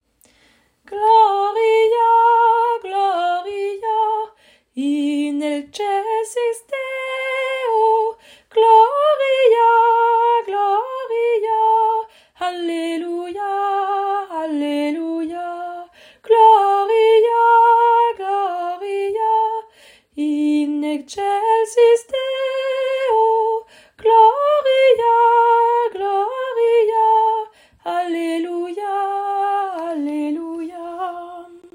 Chorale ouverte à toutes et à tous
Extrait audio de la chorale